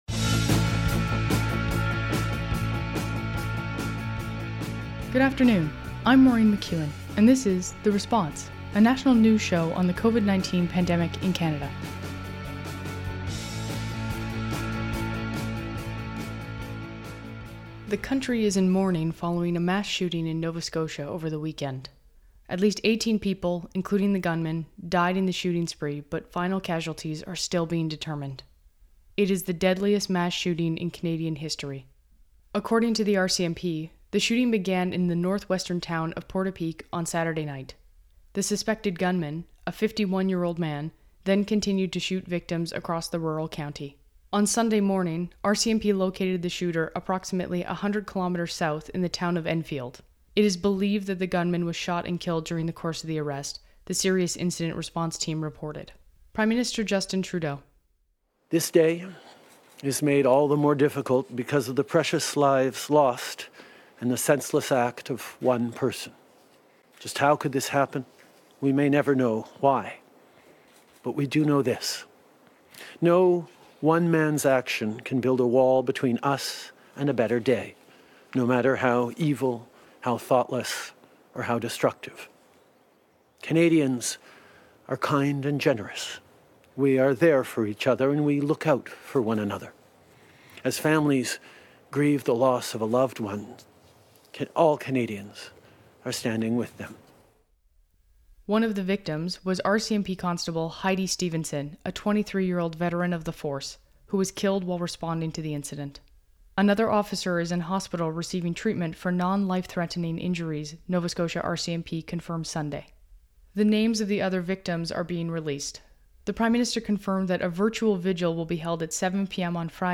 National News Show on COVID-19
Recording Location: Ottawa
We hear from Prime Minister Trudeau on the tragedy.
Type: News Reports
192kbps Stereo